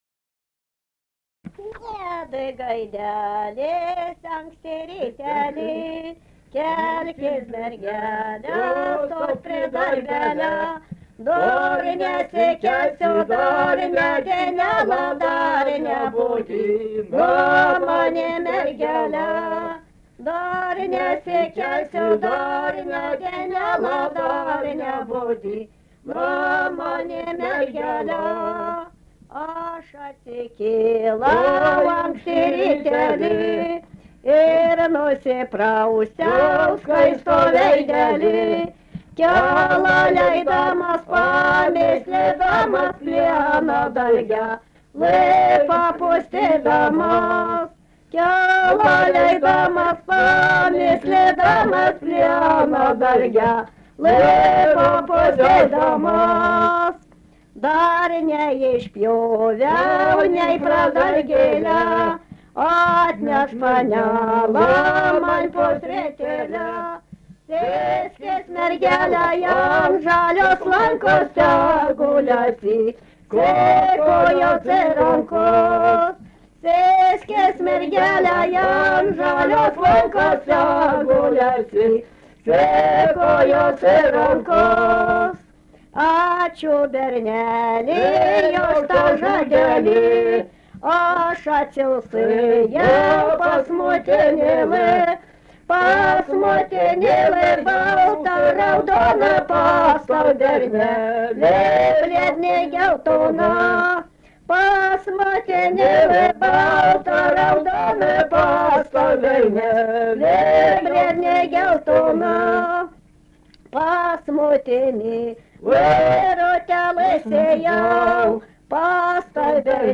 daina, kalendorinių apeigų ir darbo
Jukoniai
vokalinis